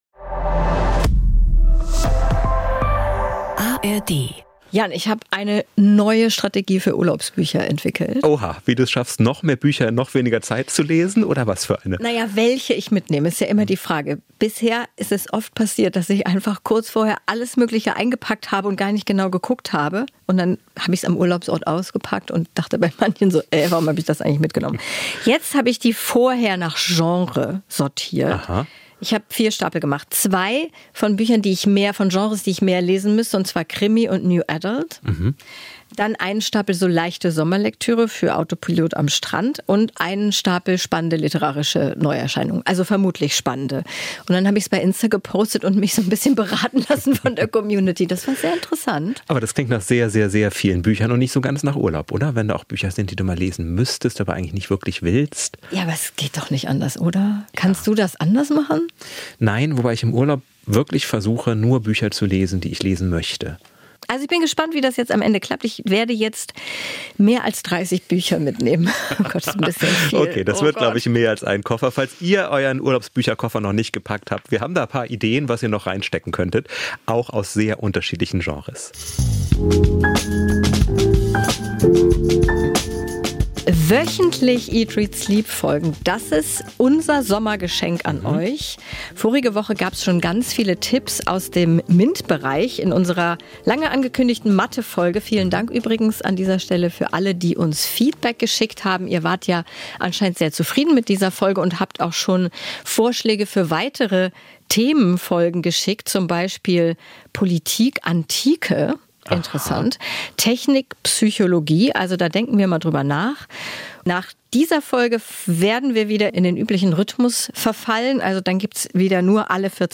Interview-Gast